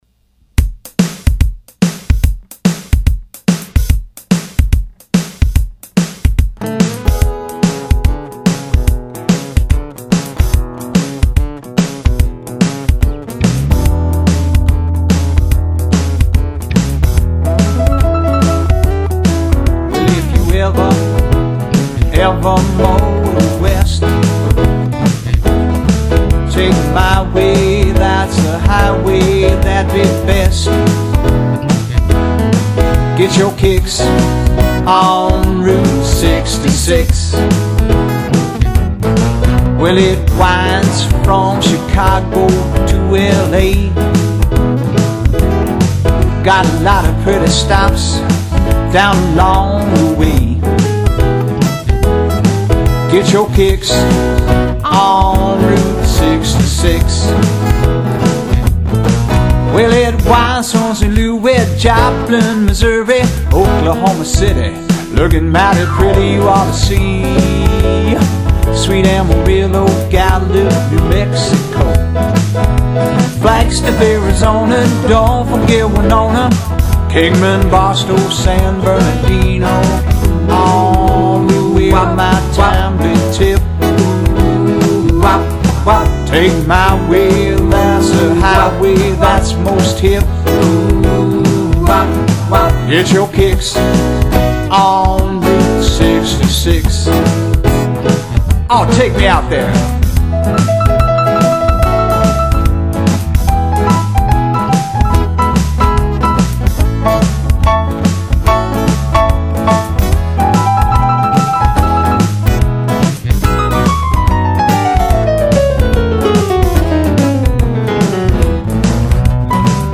This is the ultimate variety band.